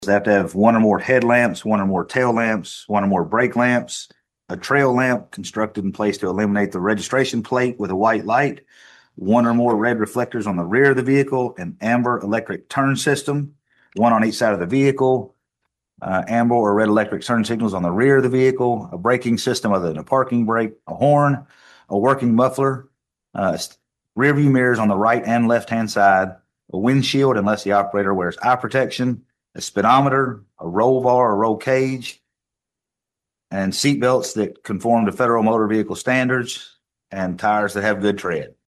Hopkins County Fiscal Court is one step closer to allowing street-legal special purpose vehicles on certain local and state roads, following the approval of an ordinance on first reading at Tuesday morning’s meeting.